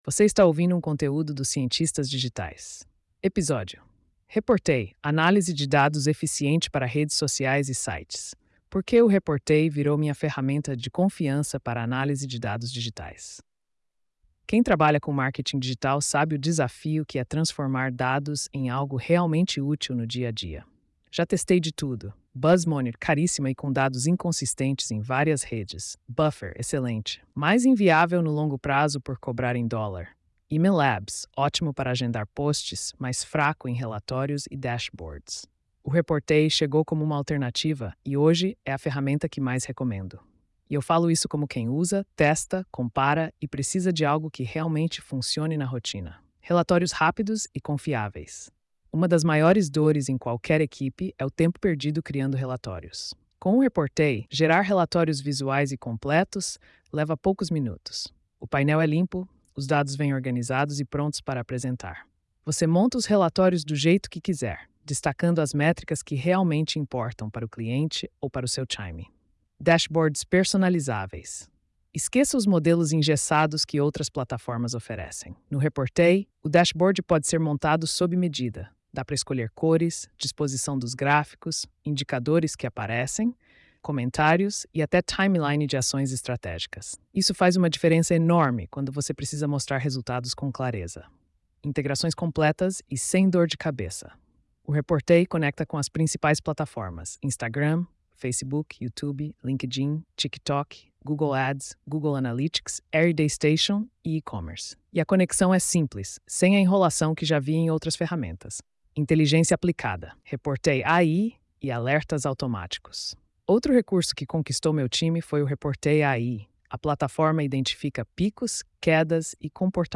post-3275-tts.mp3